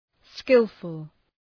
Προφορά
{‘skılfəl}